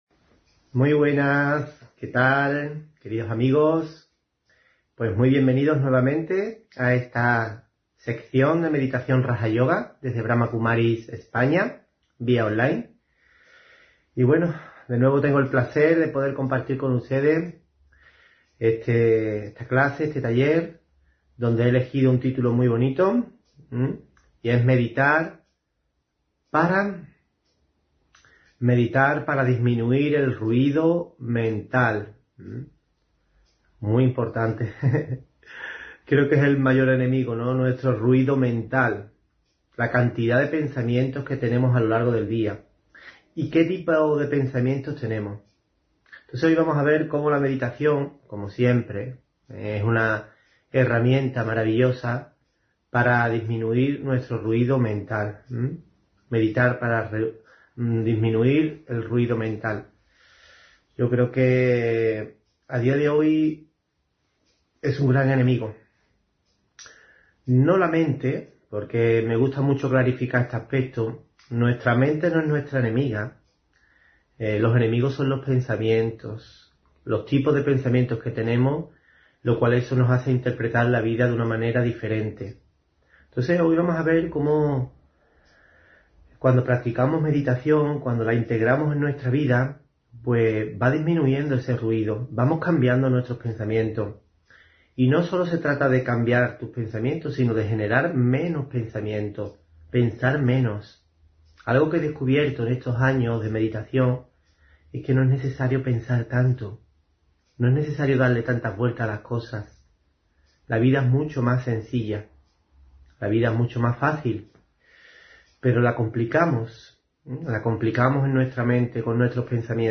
Meditación y conferencia: Sanar y proteger la tierra (22 Abril 2024)